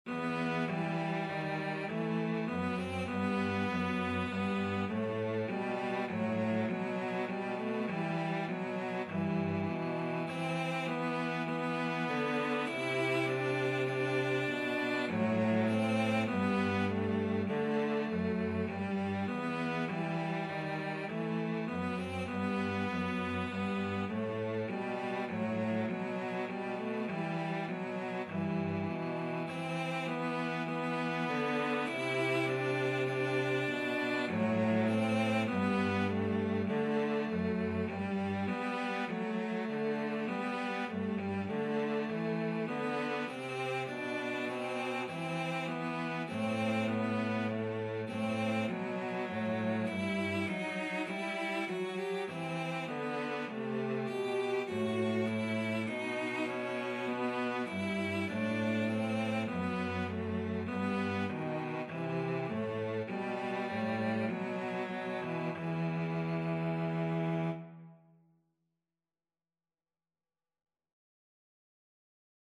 Cello 1Cello 2
Moderato
4/4 (View more 4/4 Music)
Classical (View more Classical Cello Duet Music)